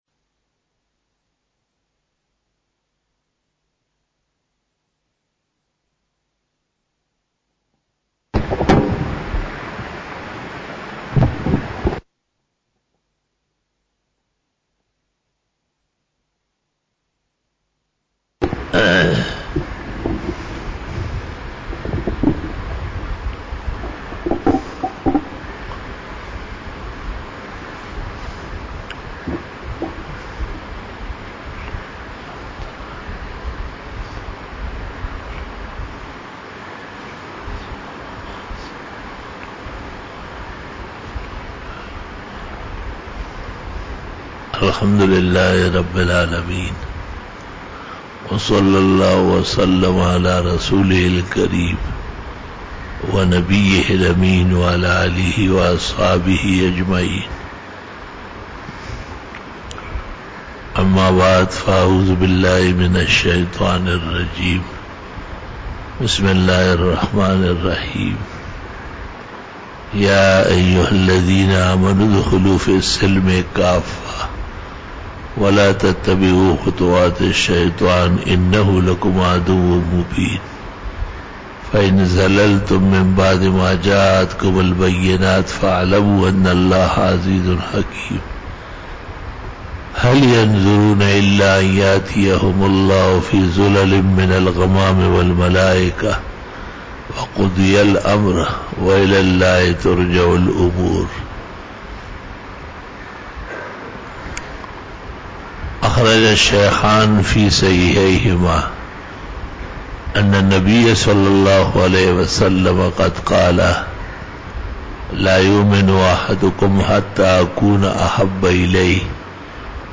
41 BAYAN E JUMA TUL MUBARAK (11 October 2019) (11 Safar 1441H)